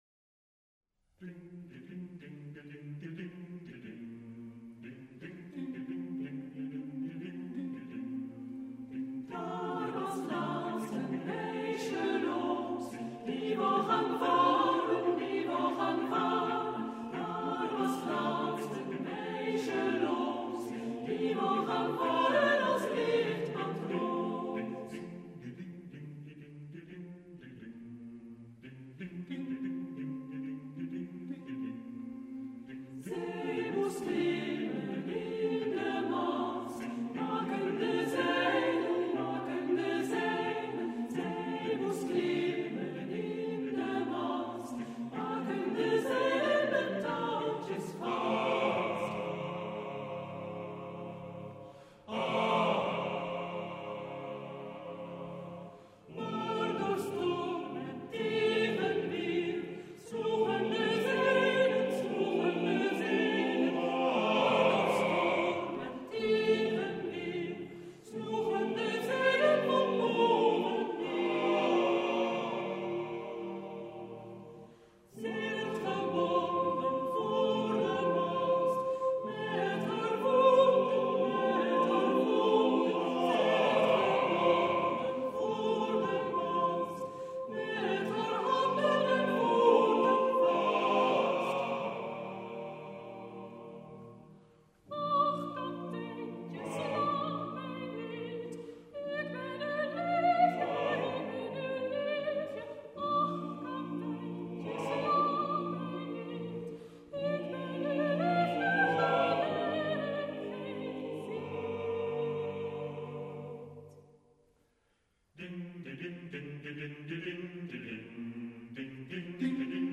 Arrangement voor koor